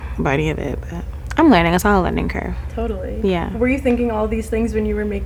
SZA_Speaking
SZA__billboard_raw_interview_104.wav